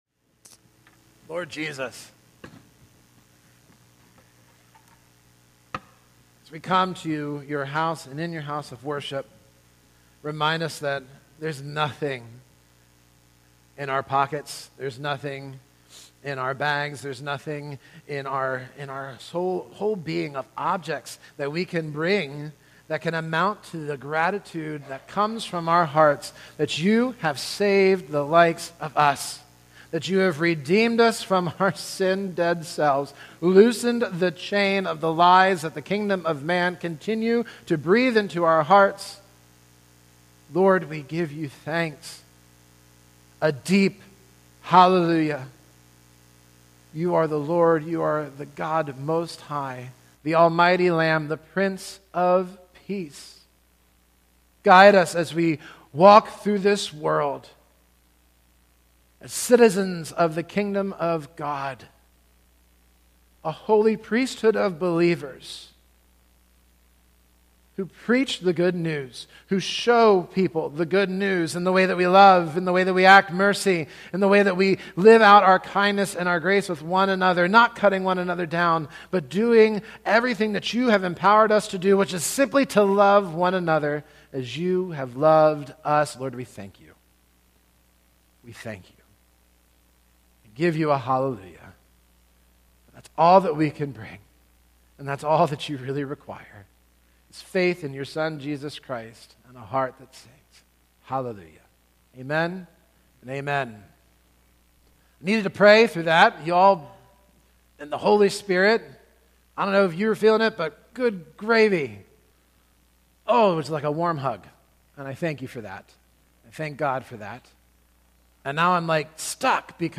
In this sermon series, we will explore Jesus’ Kingdom parables to learn what this new citizenship means for our faith, our loyalties, and our daily lives, and how Christ sends us into the world to bear witness to His Kingdom—freeing us from the idols, false promises, and misplaced hopes of the kingdom of man.